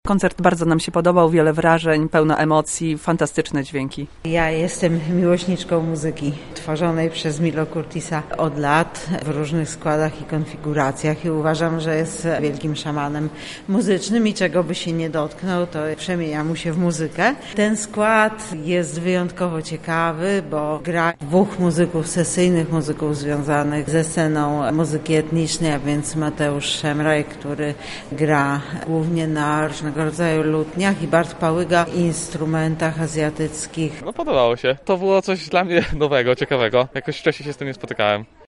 W Lublinie zabrzmiały Dźwięki Słów. Wczoraj ruszyła 6. edycja wydarzenia.